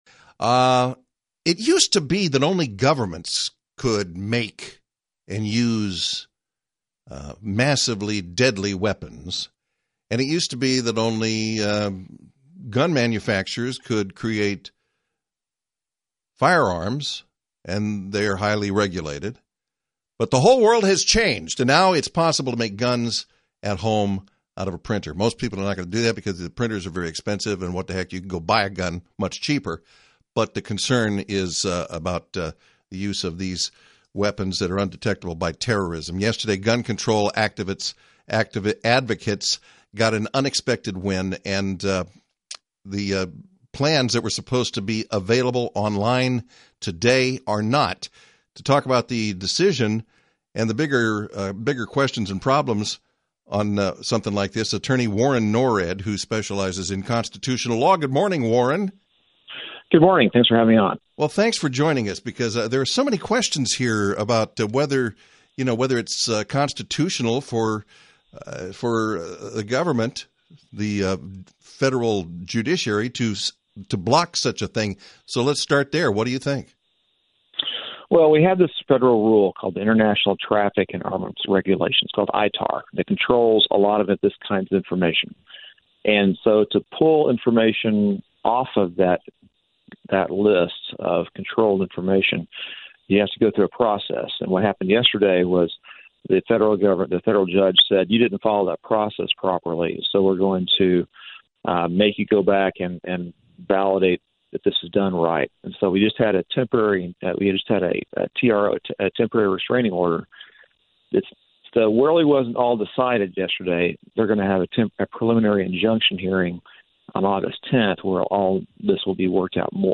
Attorney